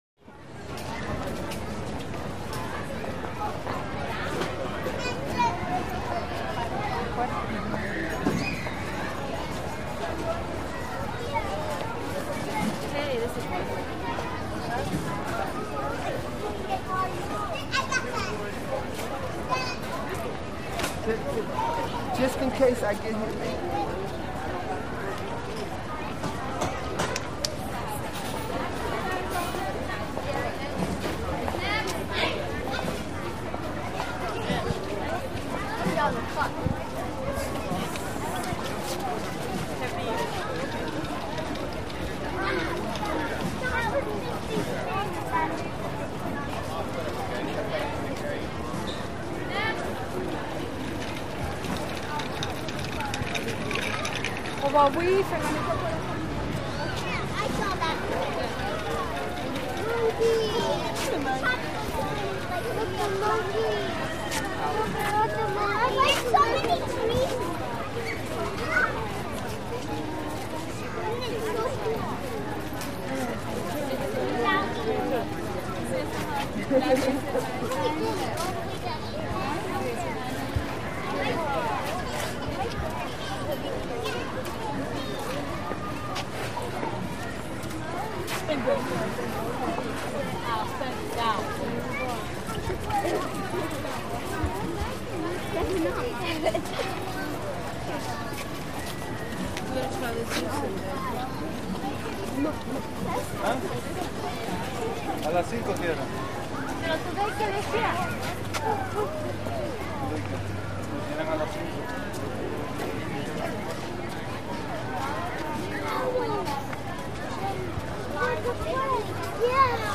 Small City Park, Close Perspective, Very Busy. Walla Close To Medium With Parents And Children Strollers By, Footsteps On Asphalt Path. Kids Yell And Play. Could Play For Zoo, Family Amusement